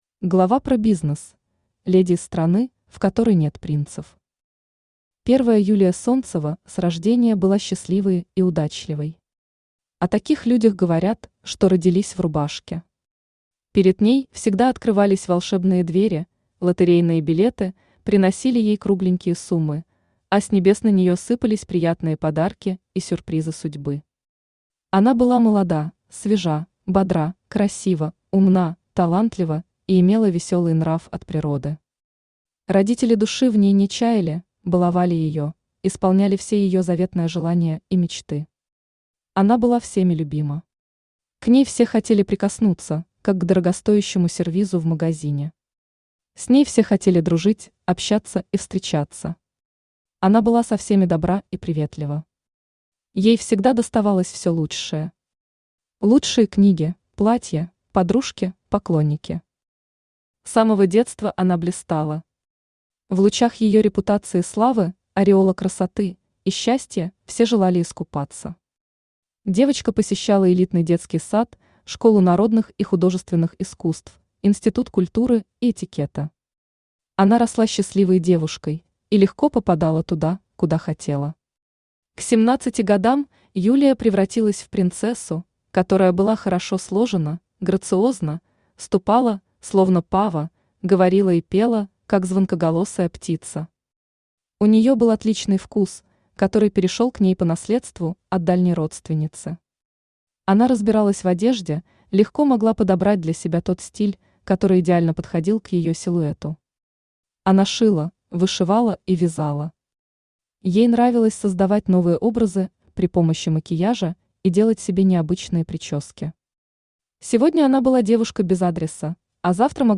Аудиокнига Бизнес Леди в Стране Принцев: Повесть | Библиотека аудиокниг
Aудиокнига Бизнес Леди в Стране Принцев: Повесть Автор Юлиана Воронина Читает аудиокнигу Авточтец ЛитРес.